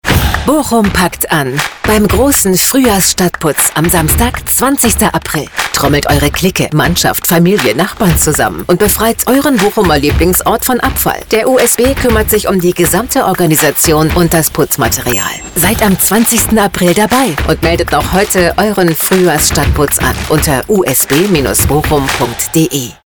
Profonde, Naturelle, Polyvalente, Chaude, Corporative
Vidéo explicative
Elle dispose d’un studio professionnel à domicile et d’une solide expérience avec de grandes marques.